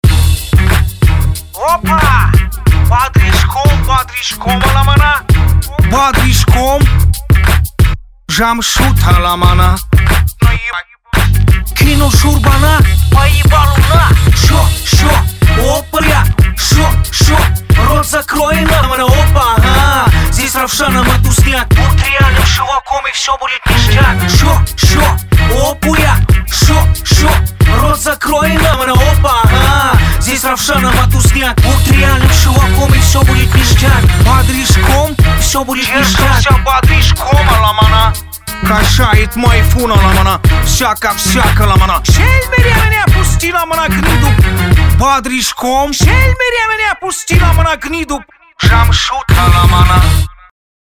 • Качество: 256, Stereo
смешные